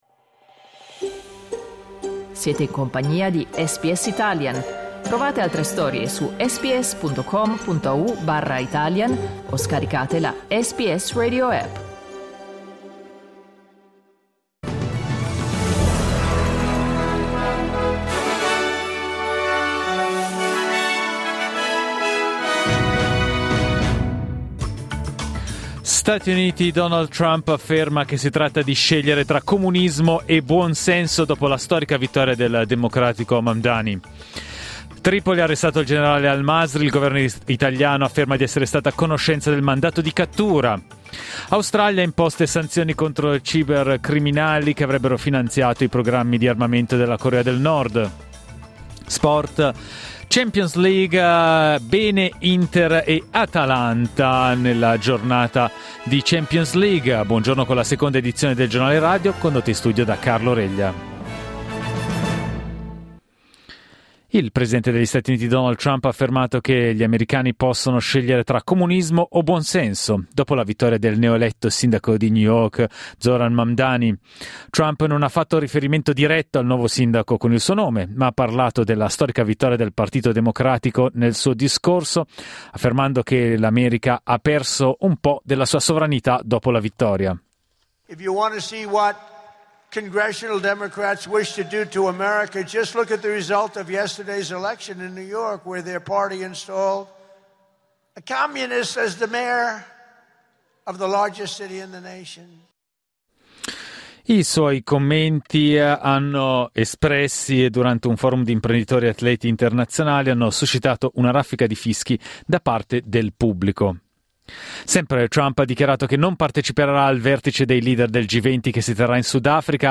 Giornale radio giovedì 6 novembre 2025
Il notiziario di SBS in italiano.